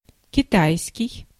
Ääntäminen
Synonyymit ха́ньский ханьский Ääntäminen Tuntematon aksentti: IPA: /kʲɪˈtajskʲɪj/ Lyhenteet ja supistumat кит.